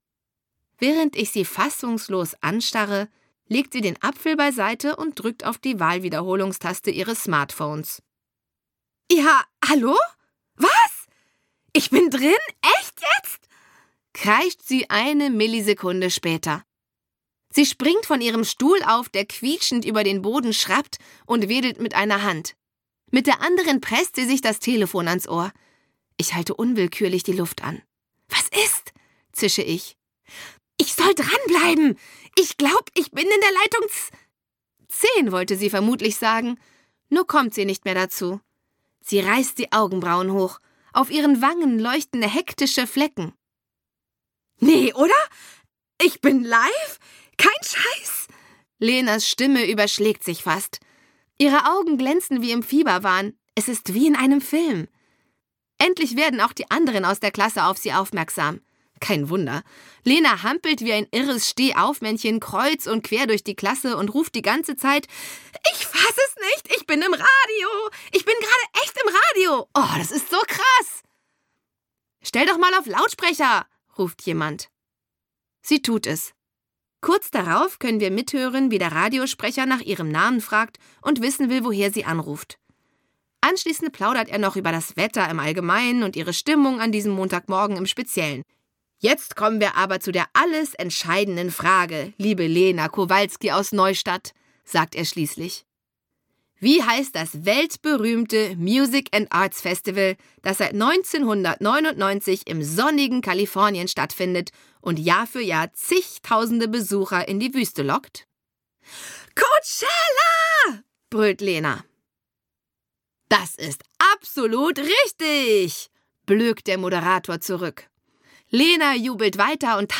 Conni 15 5: Meine Freundinnen, der Rockstar und ich - Dagmar Hoßfeld - Hörbuch - Legimi online